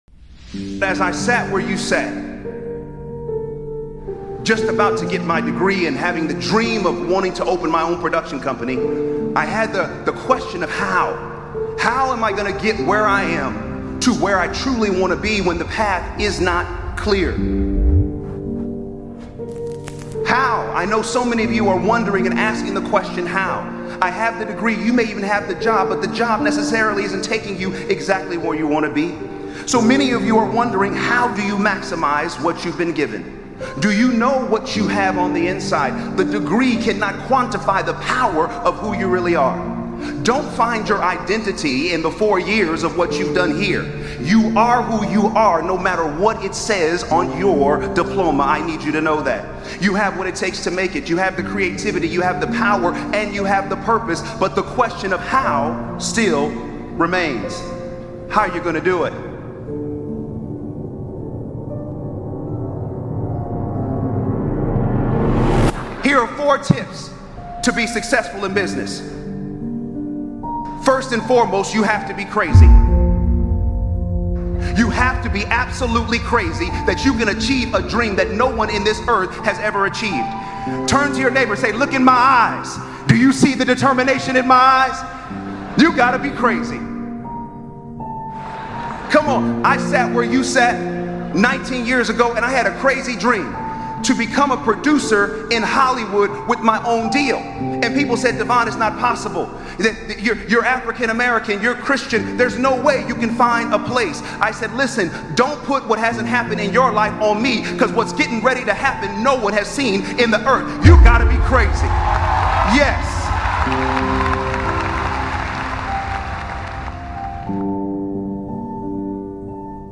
A Speech That Challenges Norms: Explore Purpose to Find Greater Direction